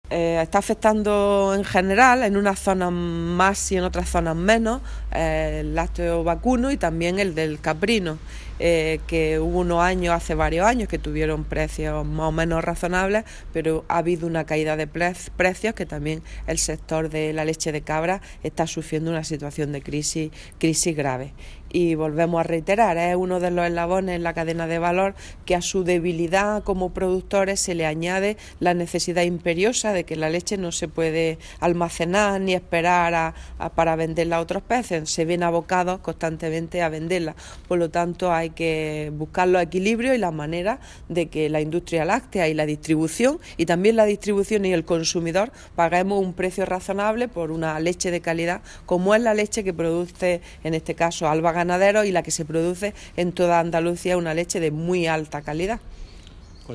Declaraciones de Carmen Ortiz sobre el precio de la leche